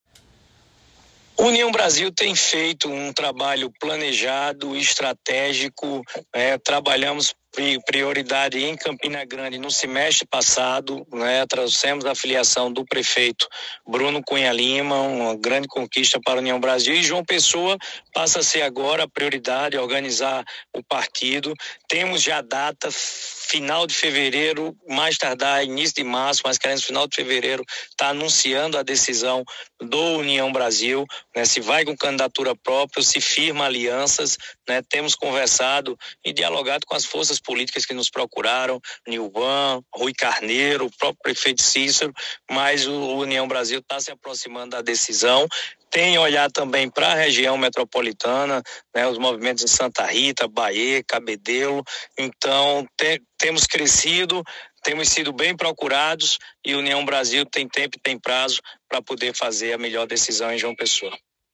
O senador Efraim Filho revelou durante entrevista ao Programa Meio-Dia Paraíba da Rádio Pop, que o União Brasil está priorizando as eleições municipais de João Pessoa.
Abaixo a fala do senador Efraim Filho.